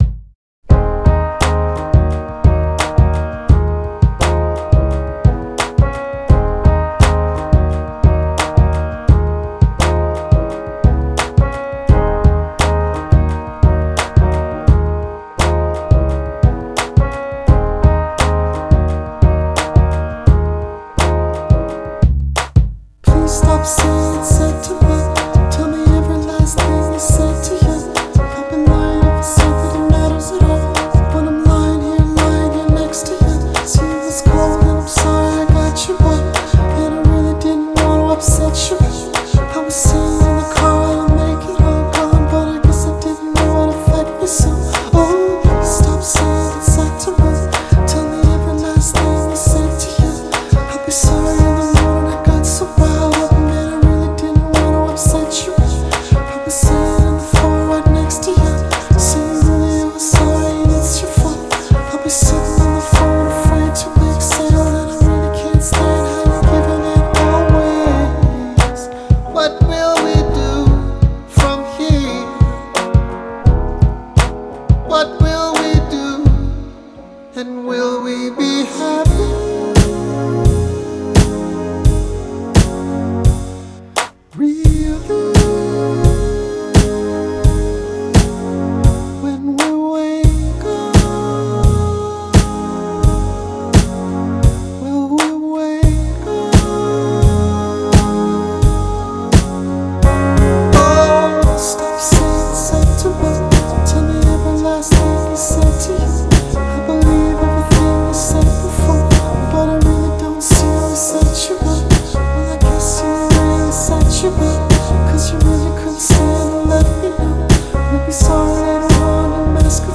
Soulful postmodern funk